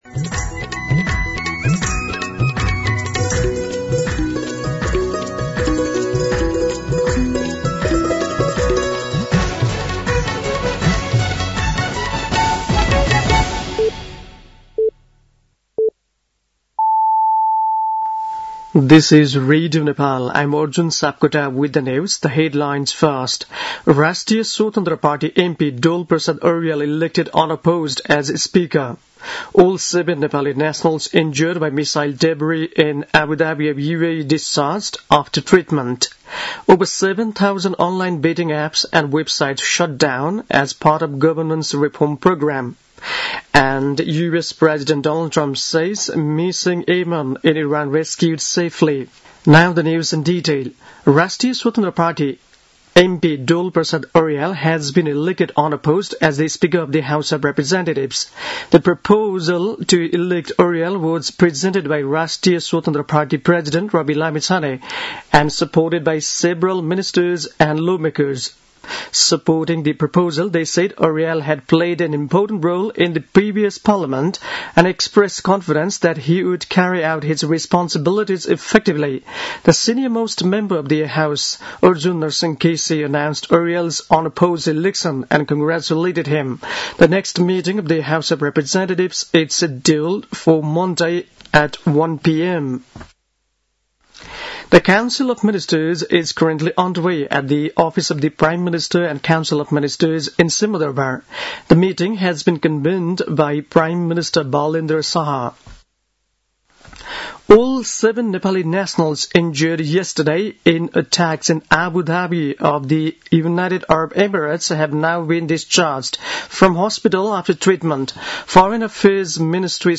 दिउँसो २ बजेको अङ्ग्रेजी समाचार : २२ चैत , २०८२
2pm-English-News-22.mp3